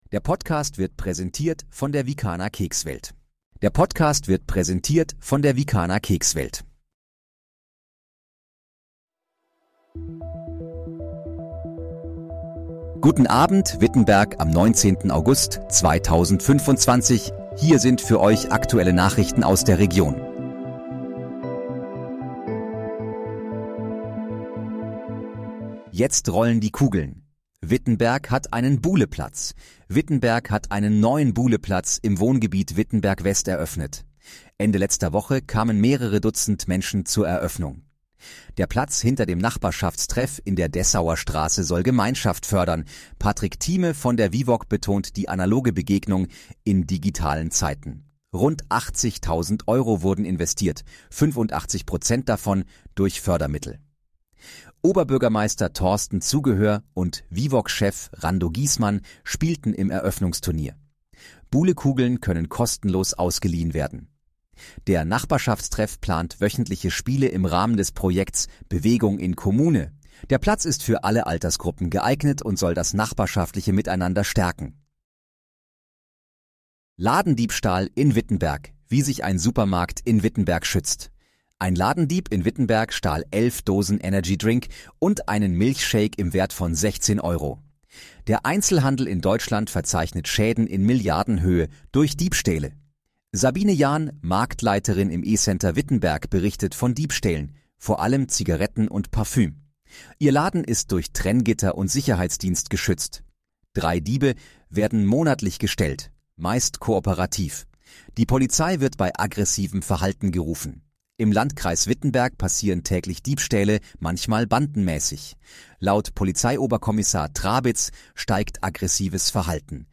Guten Abend, Wittenberg: Aktuelle Nachrichten vom 19.08.2025, erstellt mit KI-Unterstützung